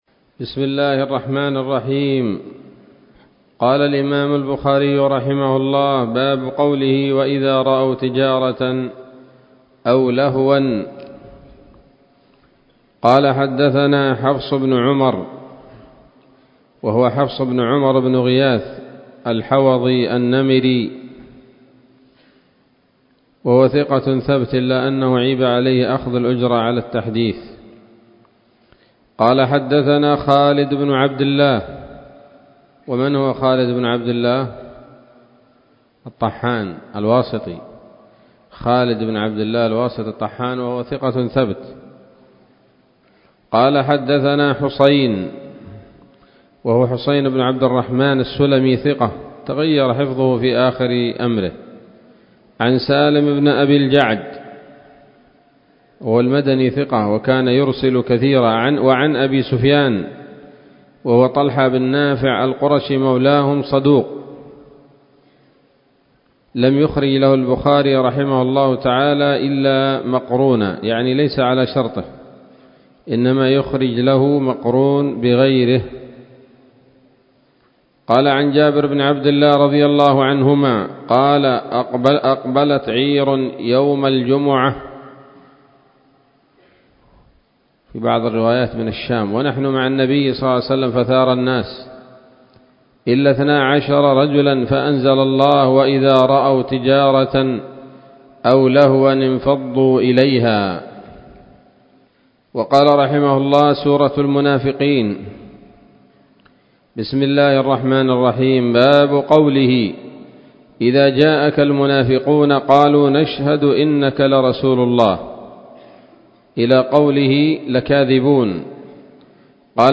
الدرس الواحد والستون بعد المائتين من كتاب التفسير من صحيح الإمام البخاري